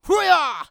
CK长声03.wav
人声采集素材/男2刺客型/CK长声03.wav